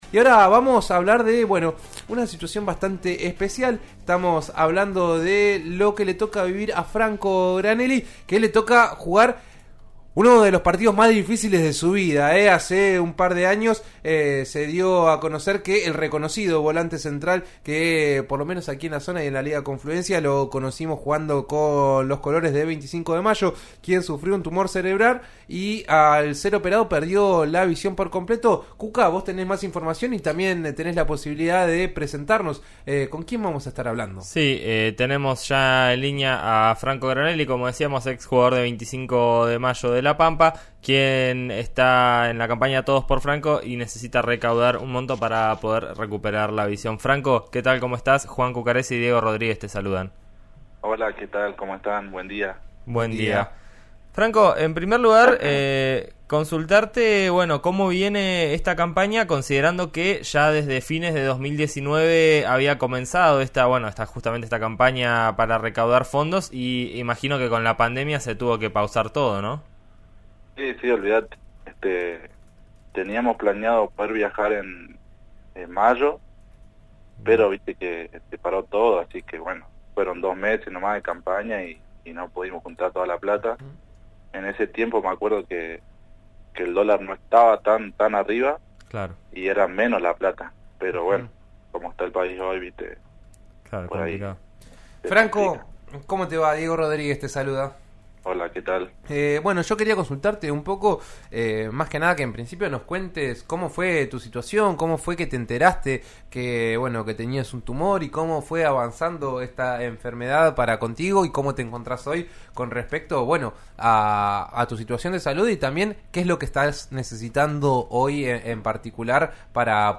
En RÍO NEGRO RADIO charlamos con el protagonista.